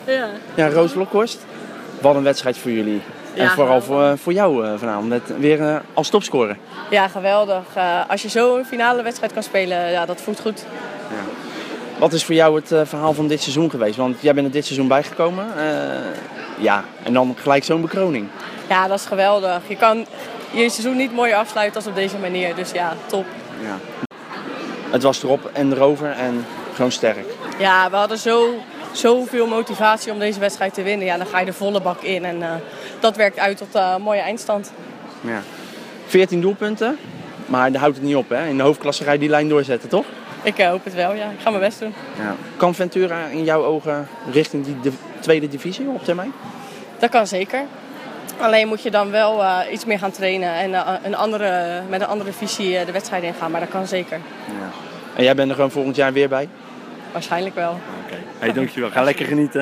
Dat zegt de voormalig international in gesprek met Waterweg Sport.